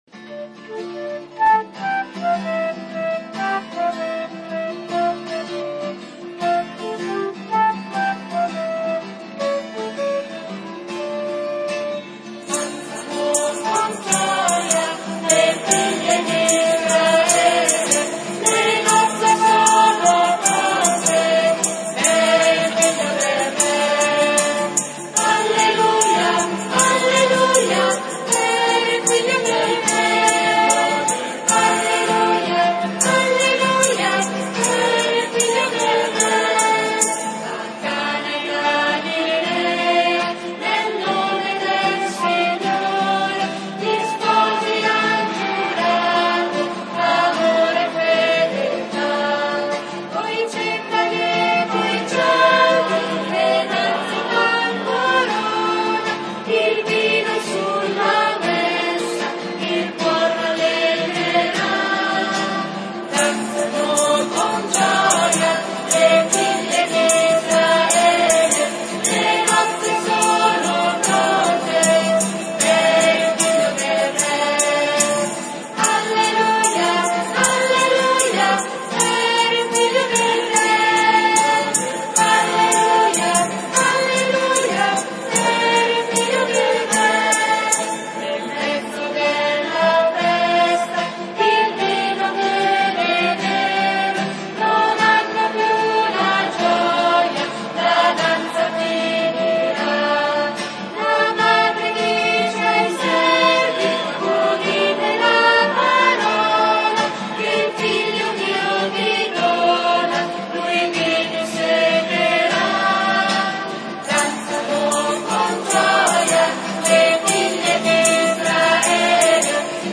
V domenica di Pasqua (Pasqua e matrimonio)
canti: